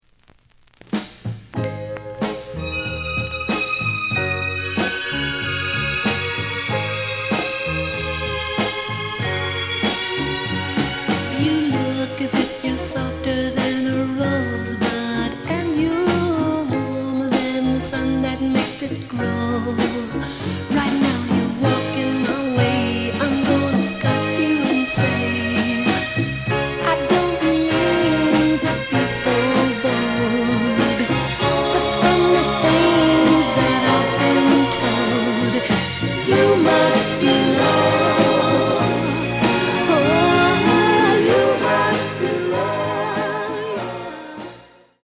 The listen sample is recorded from the actual item.
●Genre: Soul, 60's Soul